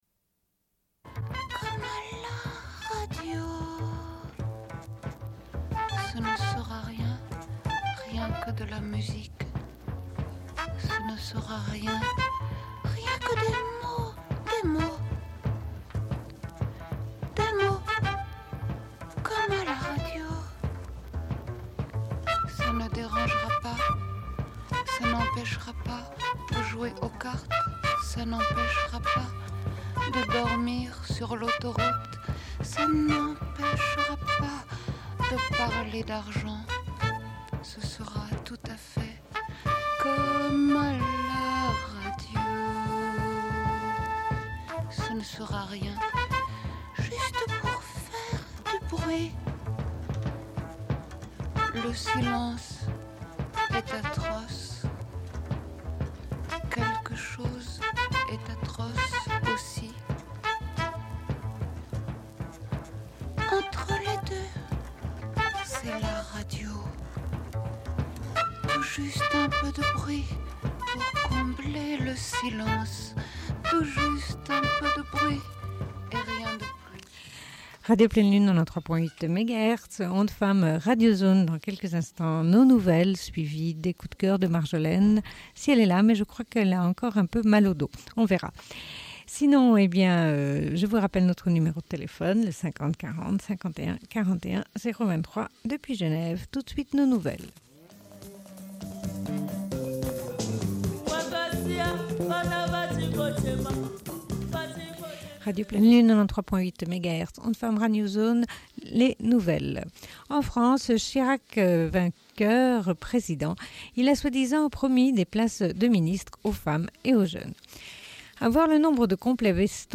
Bulletin d'information de Radio Pleine Lune du 10.05.1995 - Archives contestataires
Une cassette audio, face B31:19